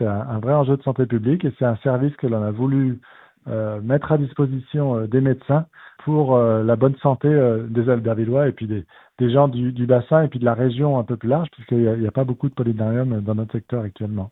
Pour le maire d’Albertville, Frédéric Burnier-Framboret, le fait de connaître les symptômes pourrait permettre de réguler le nombre de patients souhaitant se rendre dans les hôpitaux.